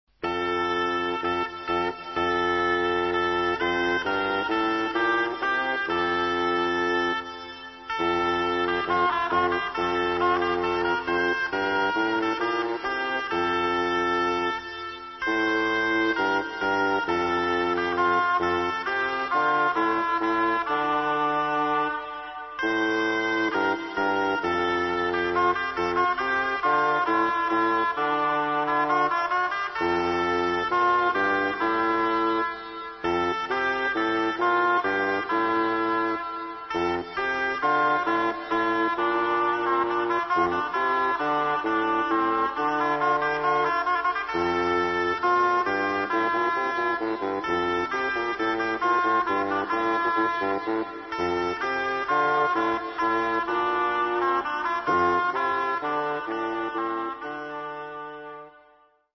A professional early music duo who specialise in performing the popular music of Elizabethan England, on authentic instruments, in period costume.